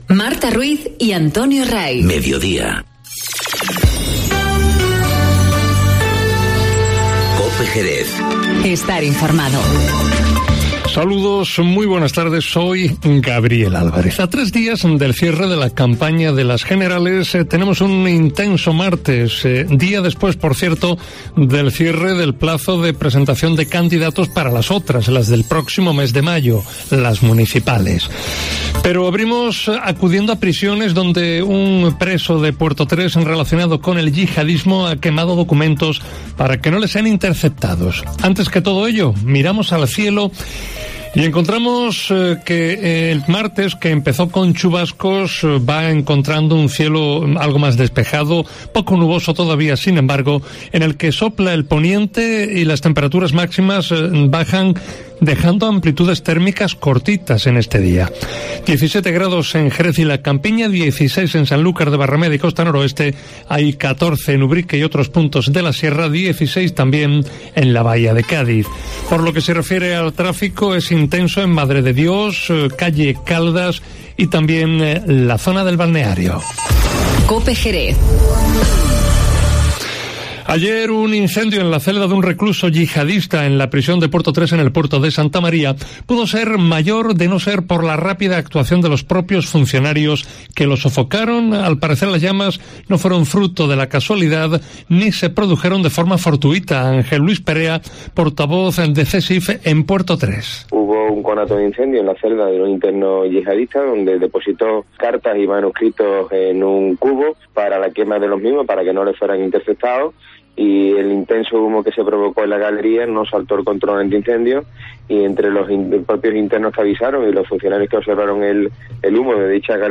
Informativo Mediodía COPE Jerez (23/4/19)